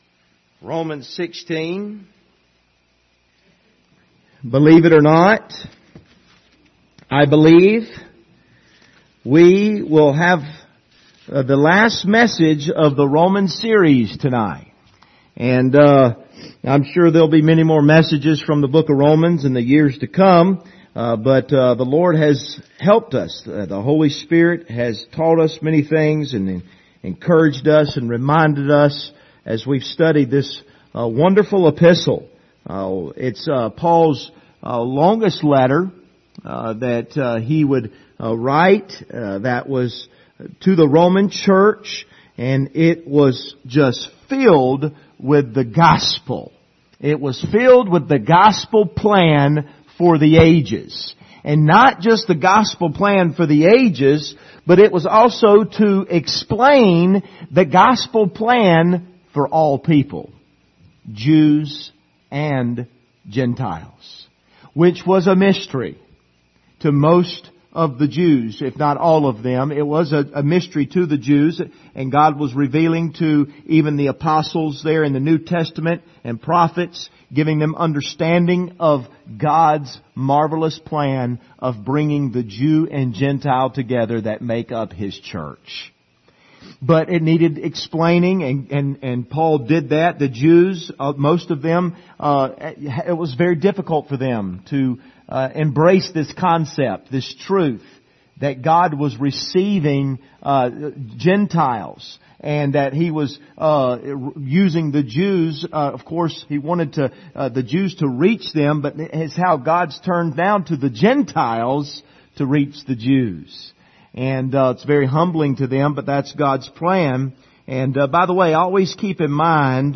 Passage: Romans 16:24-27 Service Type: Sunday Evening